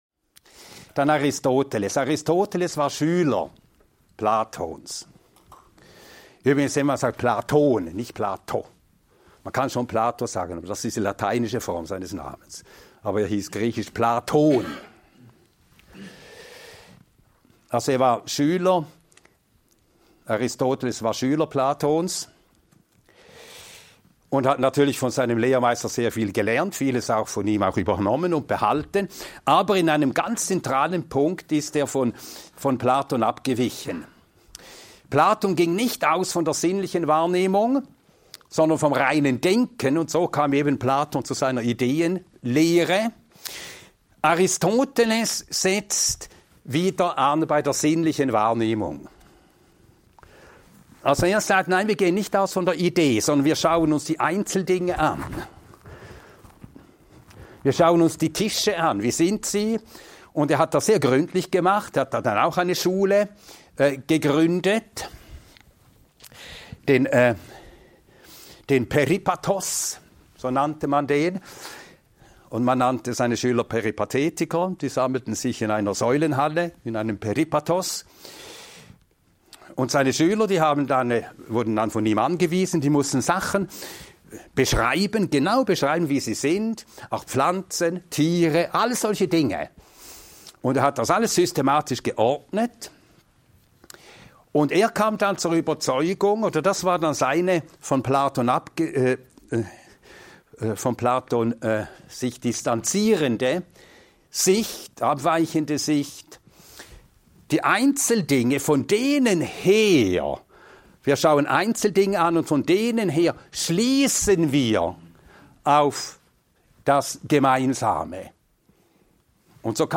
Ein mehrteiliger Vortrag